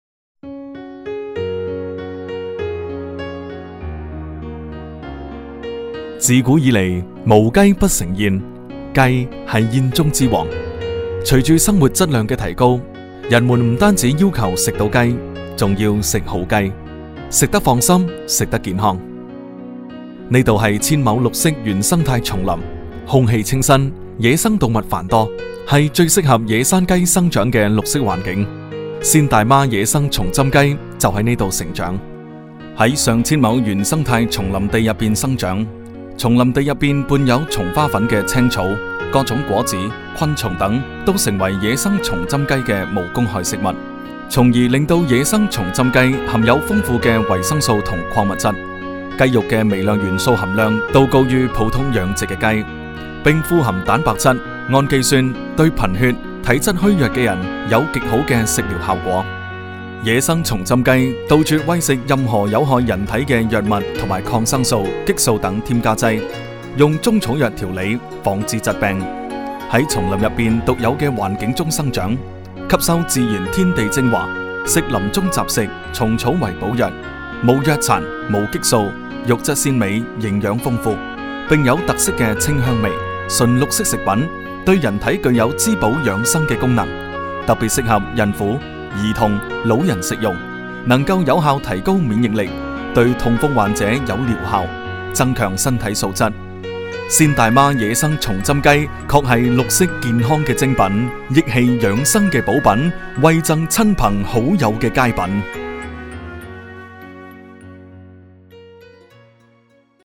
1 男粤19_专题_产品_鸡_舒缓 男粤19
男粤19_专题_产品_鸡_舒缓.mp3